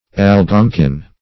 Algonquin \Al*gon"quin\, Algonkin \Al*gon"kin\, prop. n.